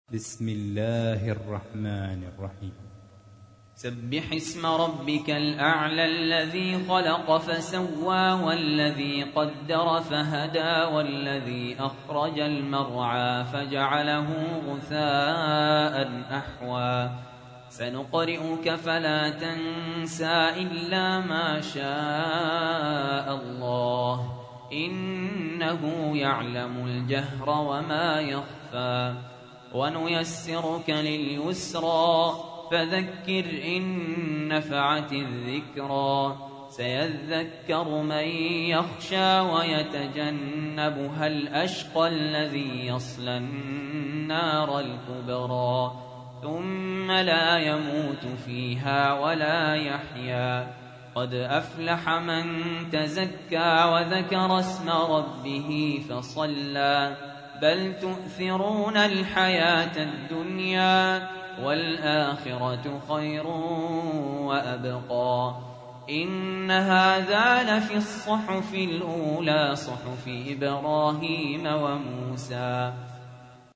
تحميل : 87. سورة الأعلى / القارئ سهل ياسين / القرآن الكريم / موقع يا حسين